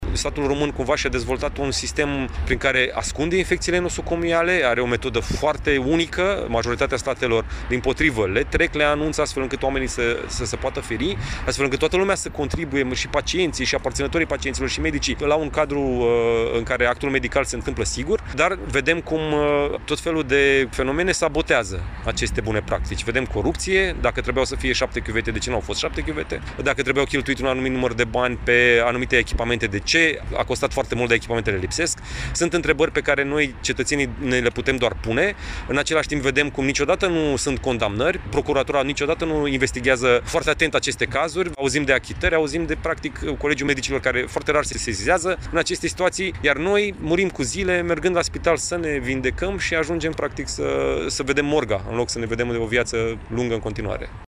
Peste o sută de cetățeni au protestat tăcut, astăzi, în Piața Națiunii din Iași , în fața Universității de Medicină și Farmacie „Grigore T. Popa”.